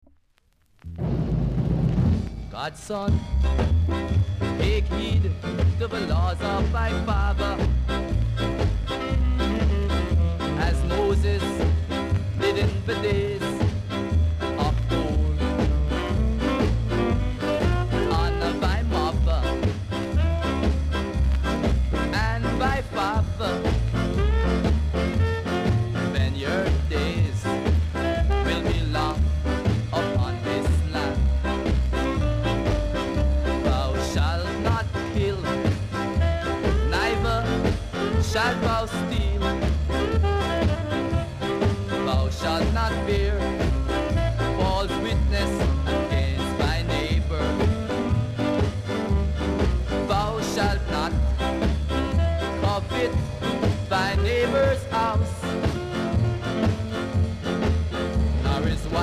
※多少小さなノイズはありますが概ね良好です。
コメント NICE SHUFFLE INST!!